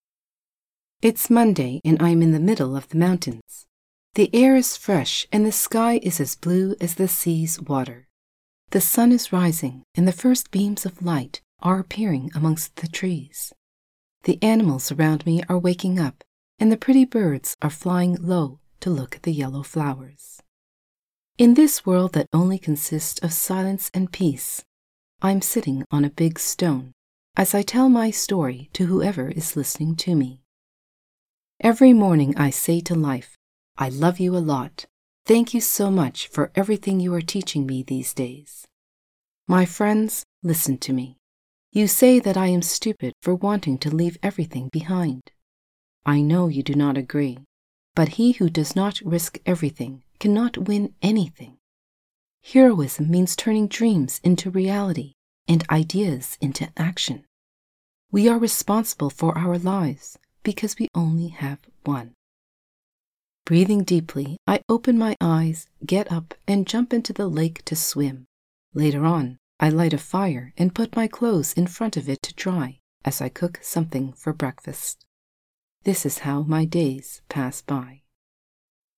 Normal
Part 1 normal (female).mp3